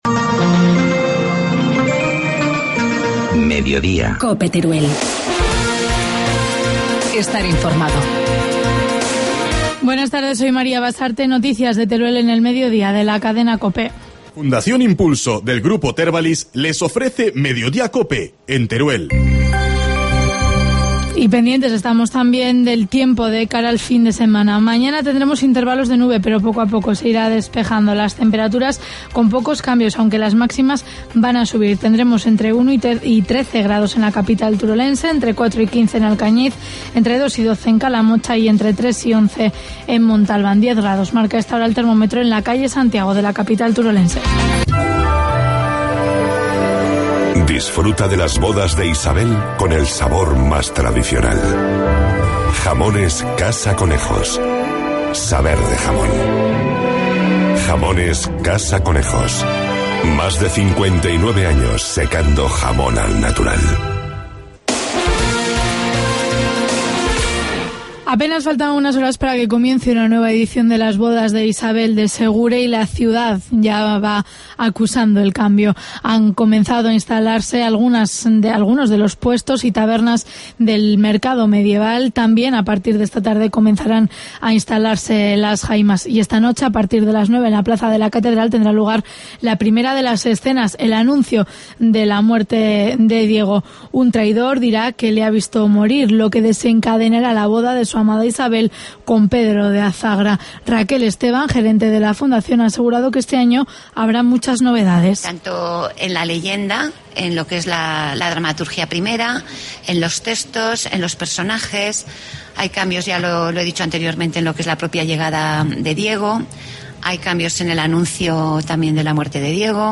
Informativo mediodía, jueves 14 de febrero